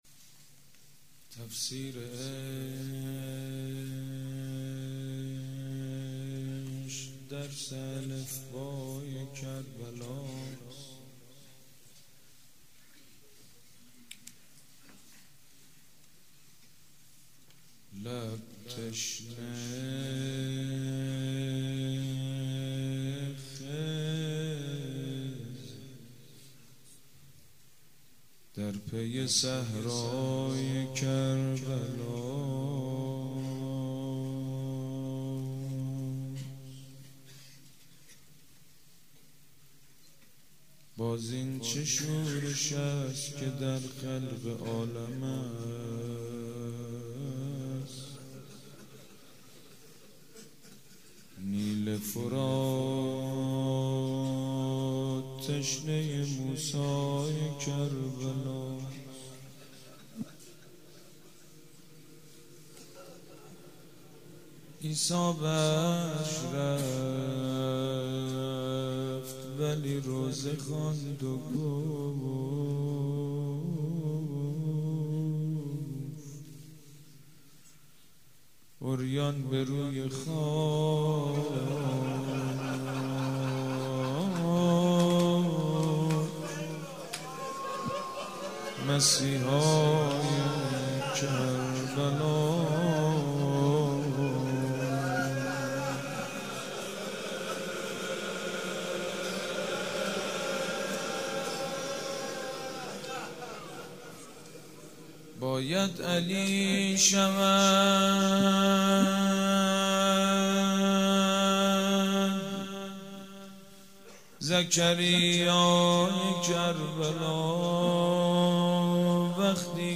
روضه شب اول مراسم عزاداری صفر
سبک اثــر روضه